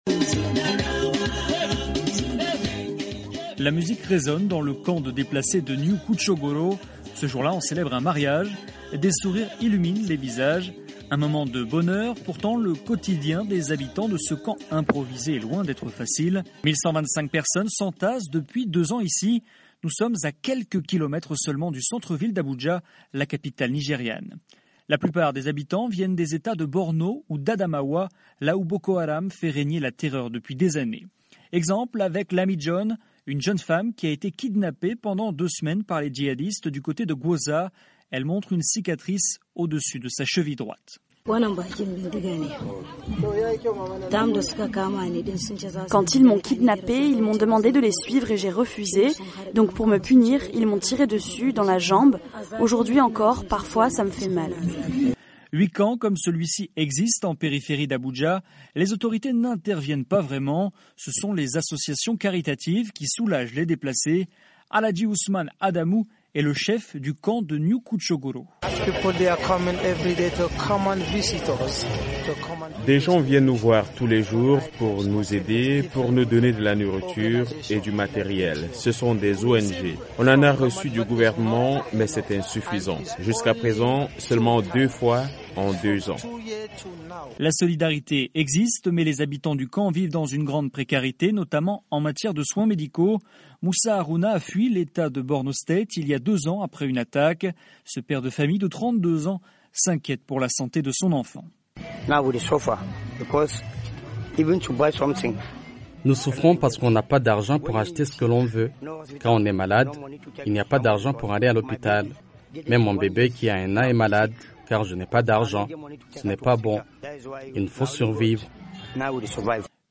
Jusque dans la capitale, on ressent toutefois les effets néfastes des jihadistes. Abuja abrite ainsi des camps de déplacés improvisés. Reportage